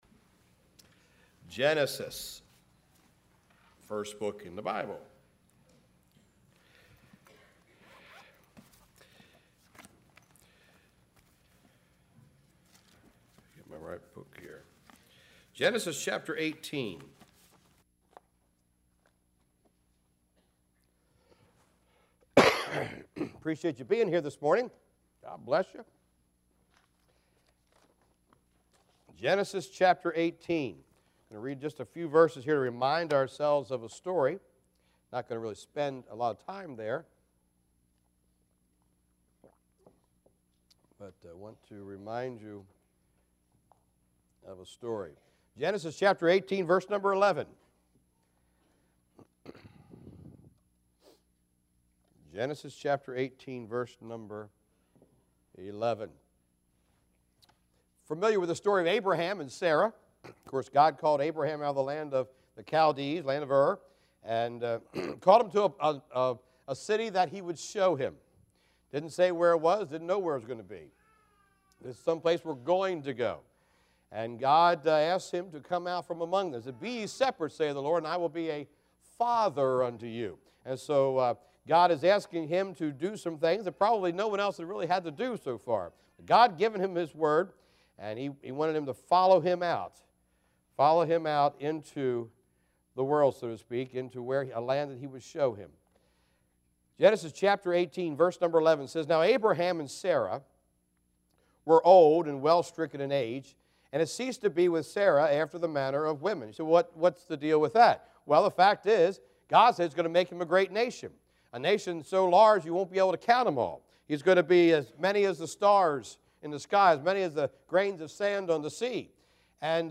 Our Hope Passage: Genesis 18:11 Service Type: Sunday Morning